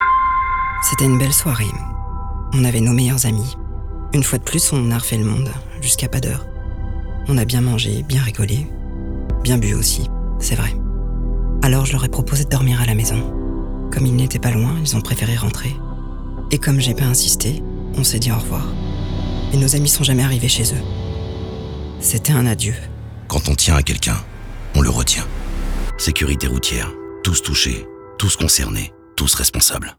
Radio_spot1_alcool_Dec2016.wav